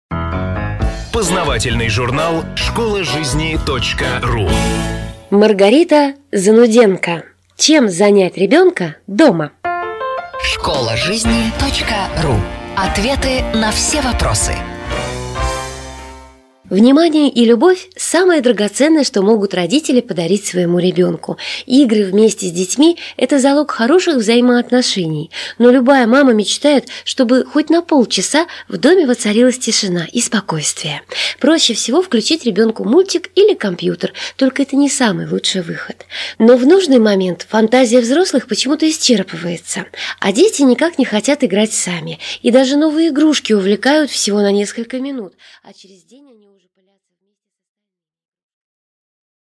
Аудиокнига Чем занять ребенка дома?